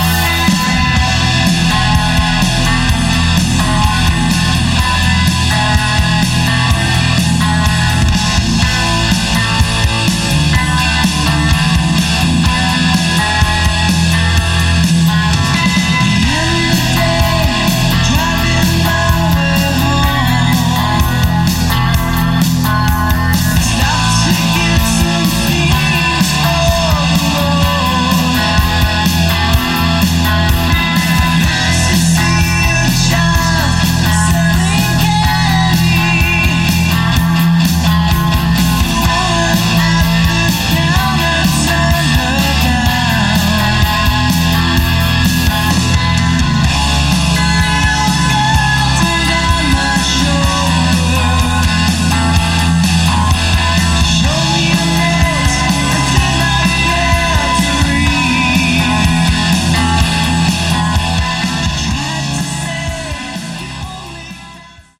Category: Hard Rock
vocals
guitars
drums
bass